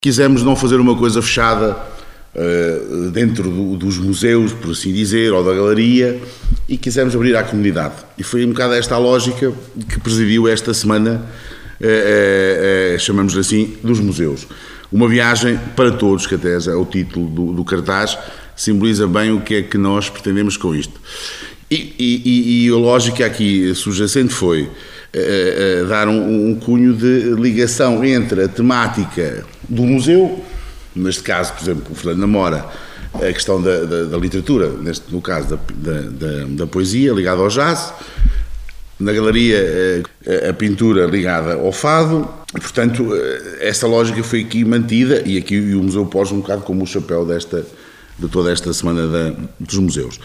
O presidente da Câmara de Condeixa, hoje (13), na apresentação da Semana dos Museus, que vai decorrer no âmbito da comemoração do Dia Internacional dos Museus, este ano com o tema “O Futuro dos Museus: recuperar e reimaginar”.